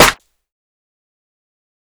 DrClap2.wav